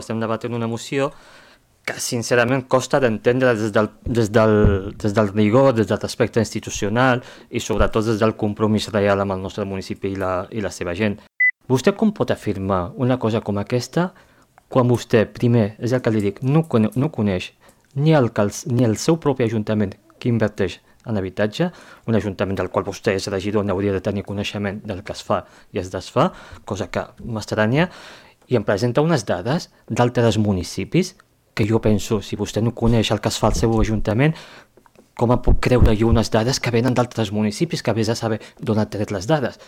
El ple municipal de Calella va viure un debat intens sobre la situació de l’habitatge arran d’una moció presentada per la CUP per completar el recompte d’habitatges buits i activar mesures municipals d’intervenció.
Per la seva banda, Soufian Laroussi, responsable de l’Àrea d’Habitatge de l’Ajuntament de Calella, va respondre qüestionant la base de la proposta i la manera com s’han presentat les dades.